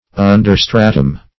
Search Result for " understratum" : The Collaborative International Dictionary of English v.0.48: Understratum \Un"der*stra`tum\, n.; pl.